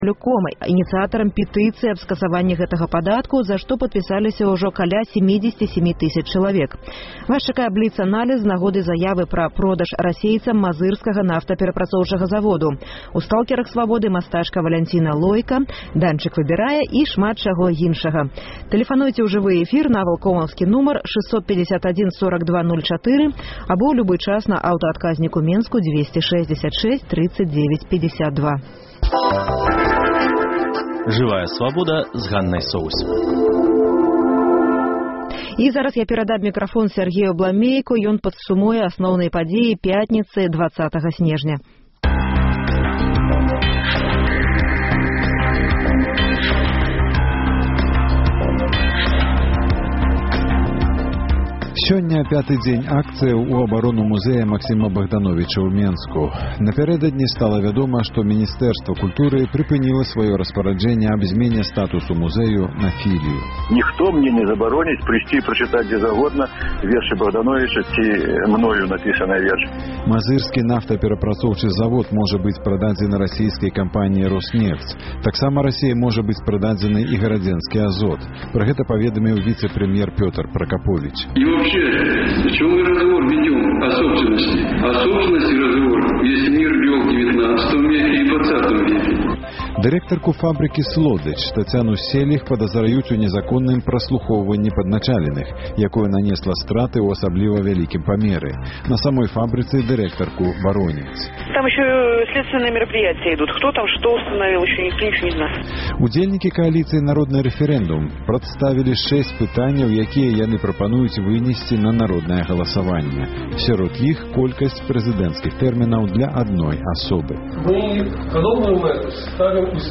Беларускія палітыкі пра тое, як пішуць прашэньні аб памілаваньні. Да акцыі пратэсту «Стоп-падатак» далучаюцца і кіроўцы грамадзкага транспарту. Рэпартаж з праспэкту Незалежнасьці.